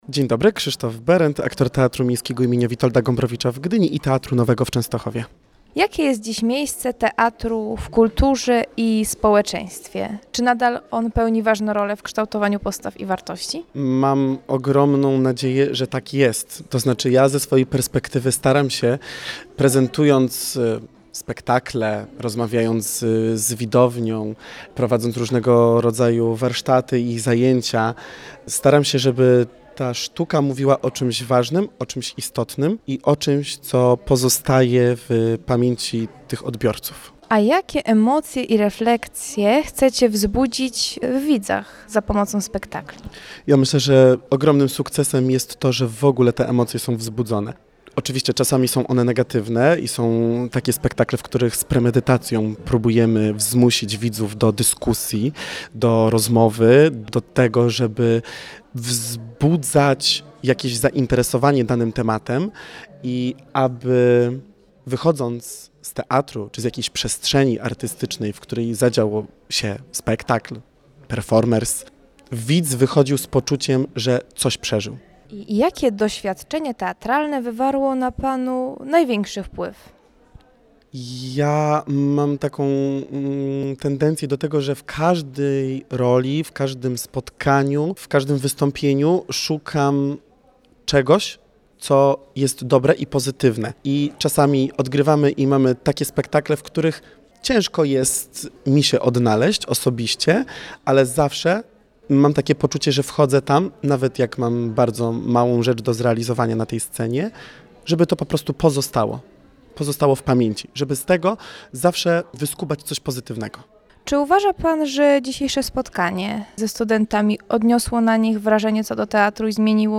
Instytut Filozofii UWM i Teatr im. Stefana Jaracza w Olsztynie zaprosiły studentów na spotkanie pod hasłem: „Kondycja teatru, kondycja sztuki, kondycja człowieka”. Posłuchajcie naszej relacji!